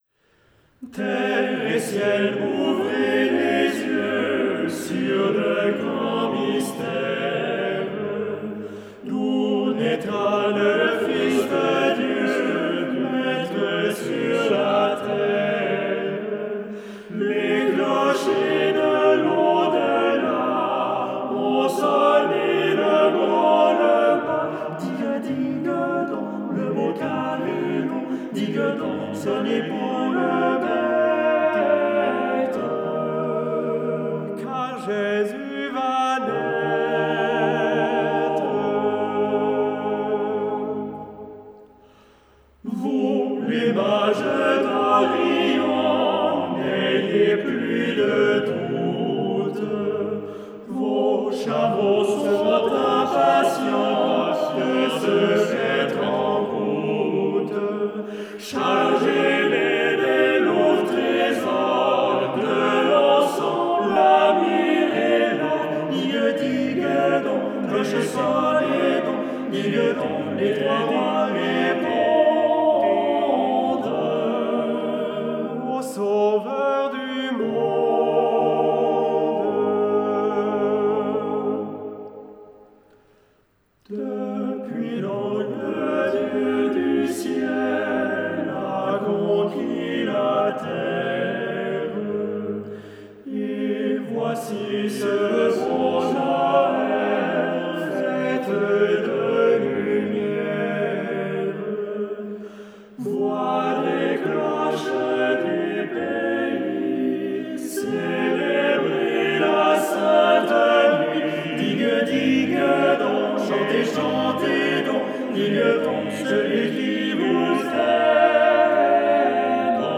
Terre et ciel…, aussi paru sous le titre de Le crépuscule, fait partie du recueil Quinze chansons populaires op. 47, qui réunit des harmonisations pour chœur à quatre voix d’hommes a cappella. Il s’agit d’un Noël catalan, dont les paroles ont été adaptées en français par Maurice Budry.